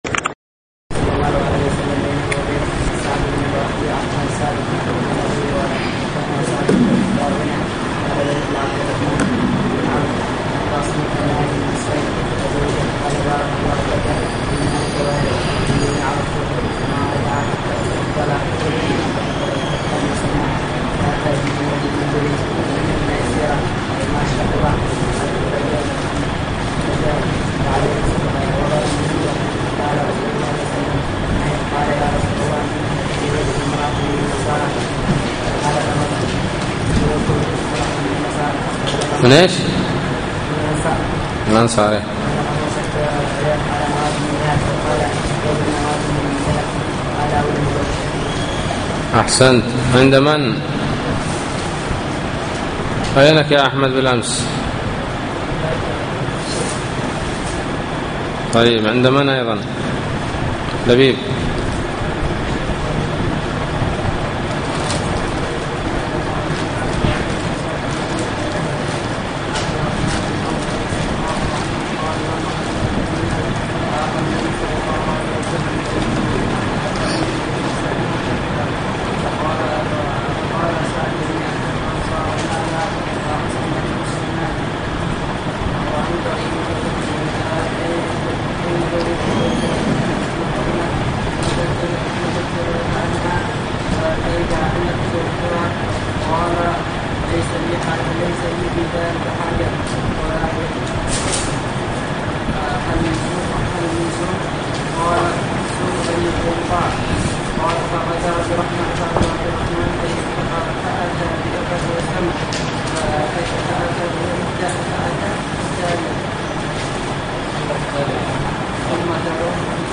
الدرس الثالث : بَاب: الْحَلَالُ بَيِّنٌ، وَالْحَرَامُ بَيِّنٌ، وَبَيْنَهُمَا مُشَبَّهَاتٌ